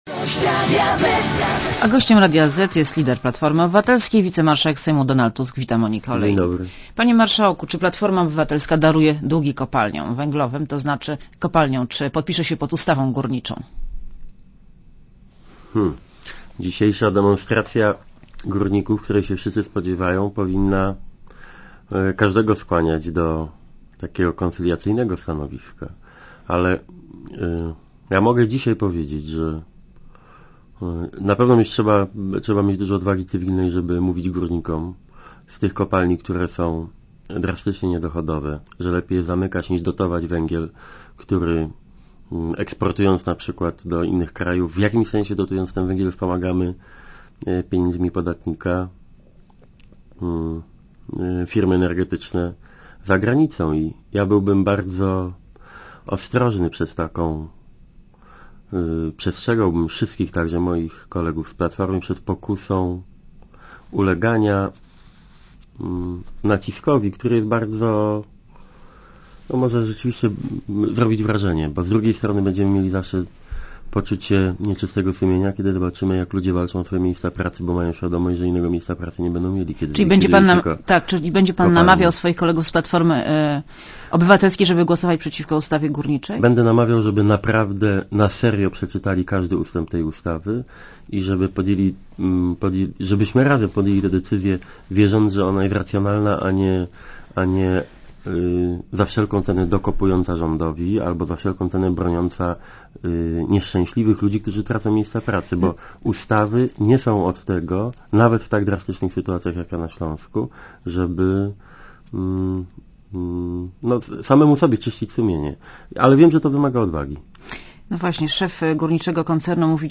Donald Tusk w Radiu Zet (RadioZet)
(RadioZet) Źródło: (RadioZet) Posłuchaj wywiadu (2,81 MB) Panie Marszałku, czy Platforma Obywatelska daruje długi kopalniom węglowym, to znaczy czy podpisze się pod ustawą górniczą?